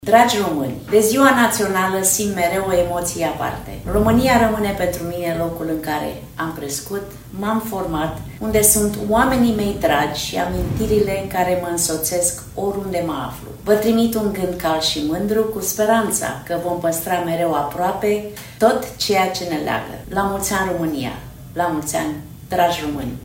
Stabilită în Statele Unite, fosta gimnastă Nadia Comăneci a transmis un mesaj de Ziua Națională a României.